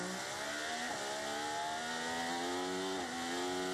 Team Radio (1)